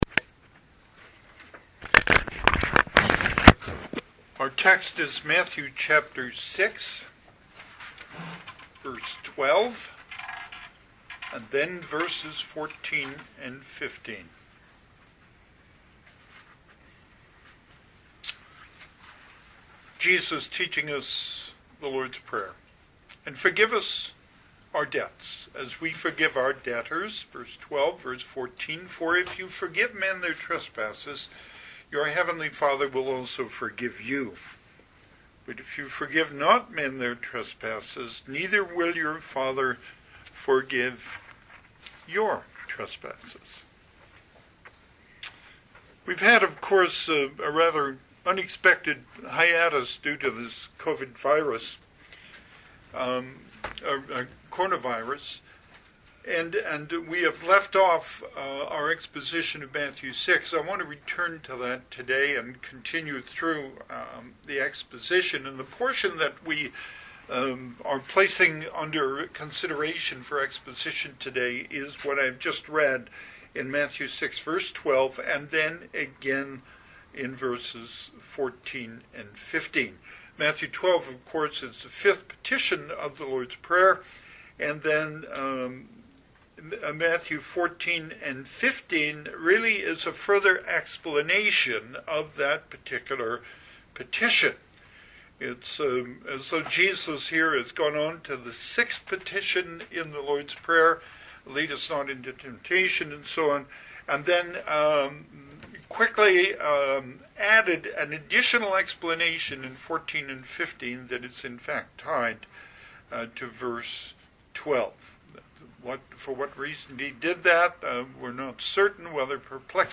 Passage: Matthew 6:12,14-15 Service Type: Sunday AM